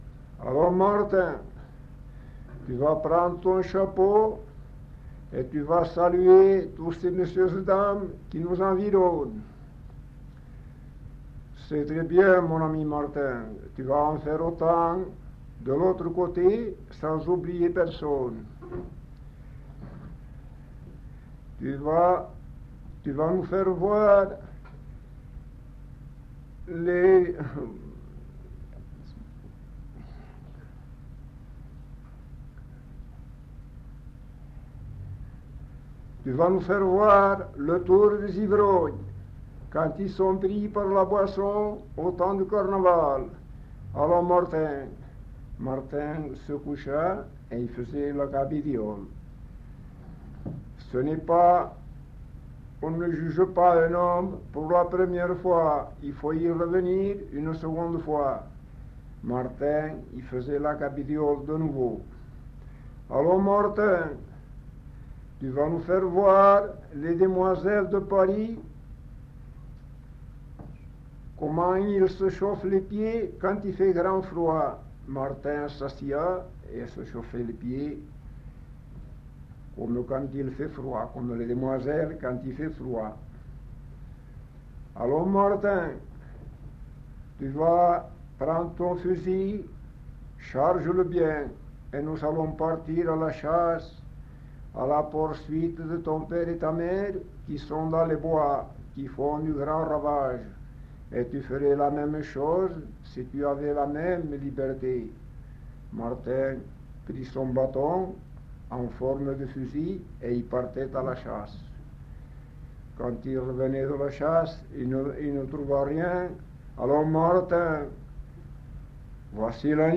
Le discours du montreur d'ours